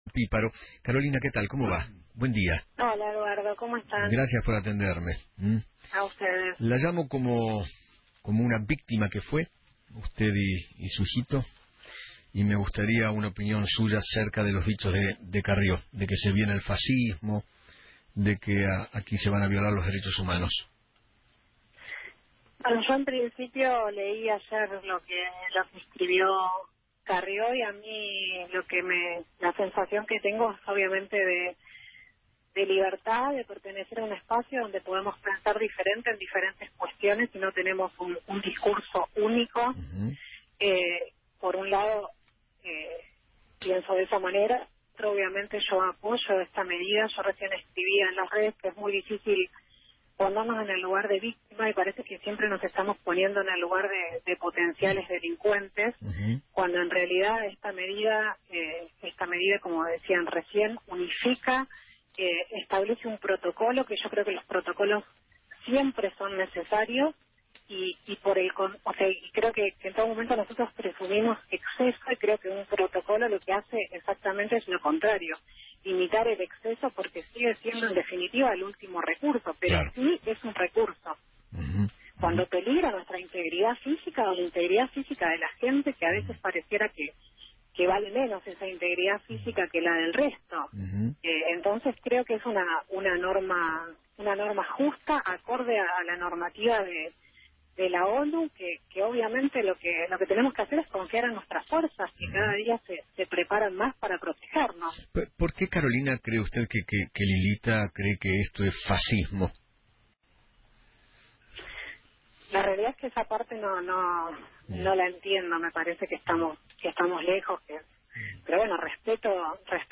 Carolina Píparo, Diputada de la Provincia de Buenos Aires por Cambiemos, habló en Feinmann 910 y dijo que  “Leí ayer lo que escribió Elisa Carrió; la sensación que tengo yo es de libertad, de pertenecer a un espacio donde podemos plantear diferencias y no tener un pensamiento único”.